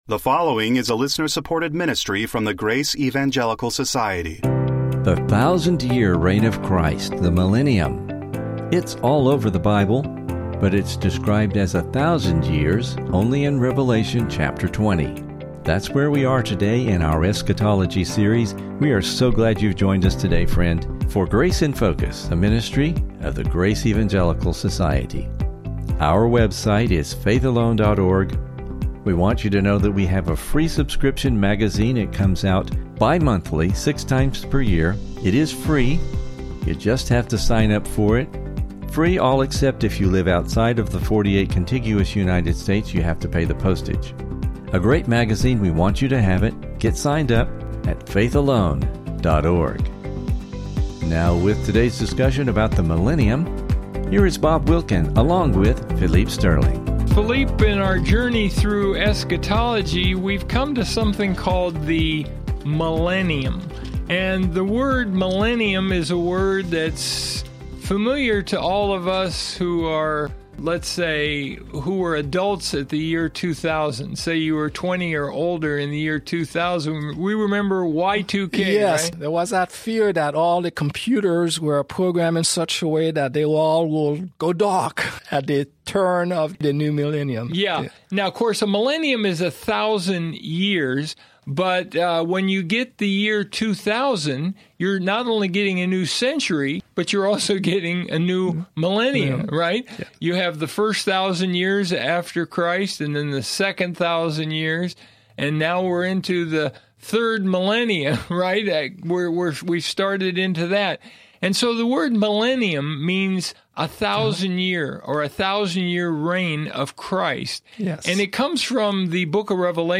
Please listen for a most interesting discussion and description of the Millennium, and never miss an episode of the Grace in Focus Podcast!